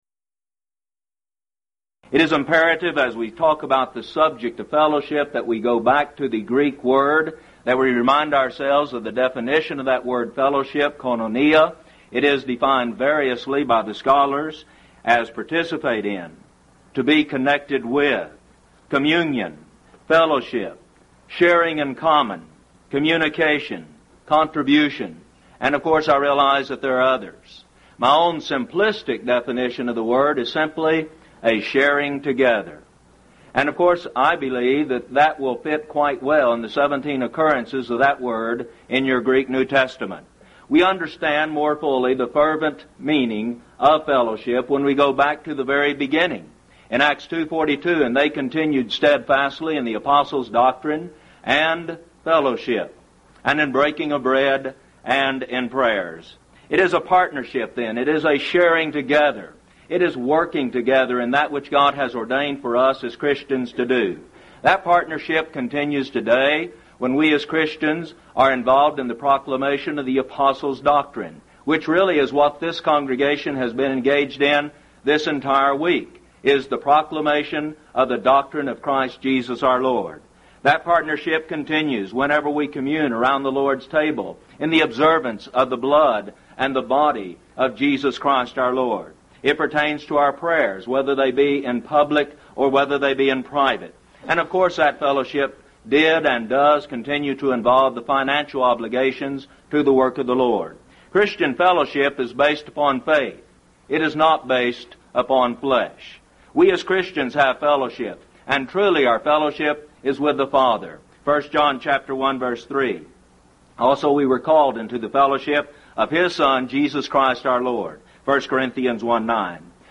Event: 1st Annual Lubbock Lectures
lecture